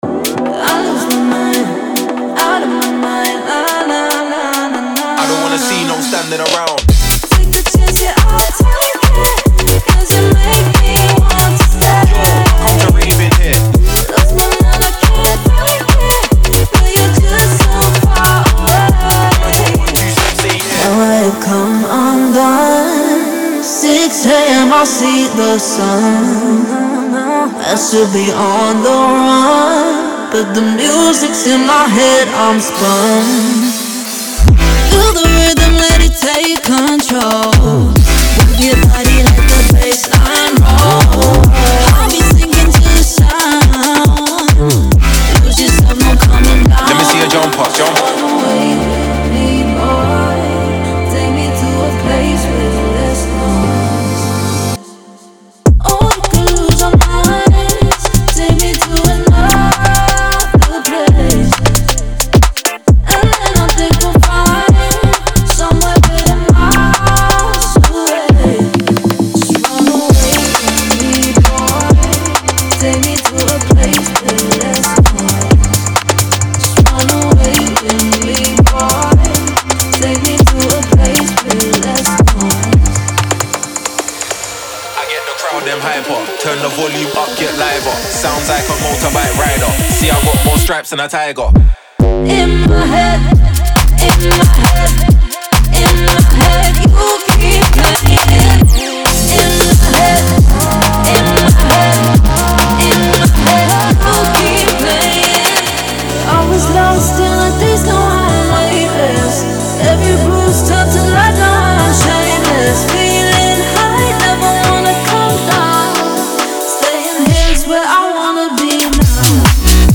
Genre:Garage
トラックにソウルフルなエネルギーと生のアティチュードを注ぎ込むのに最適です。
デモサウンドはコチラ↓
33 x Vocal Hooks